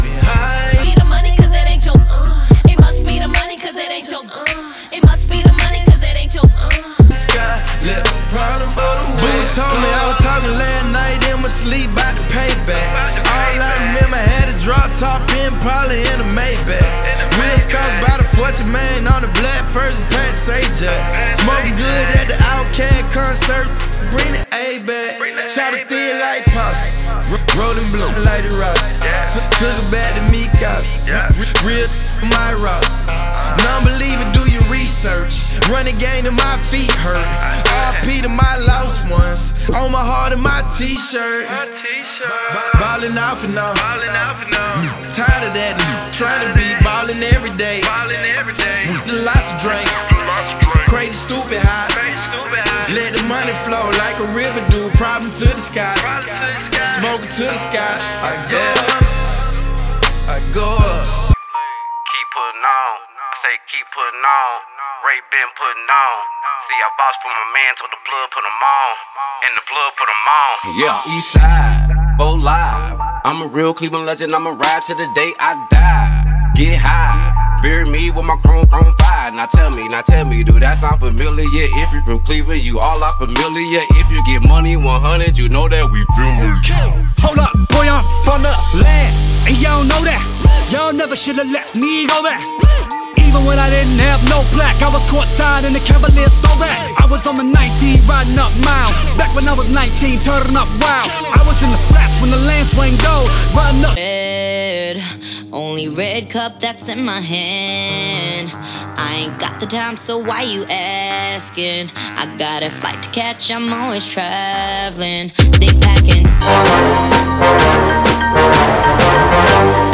DJ Mixes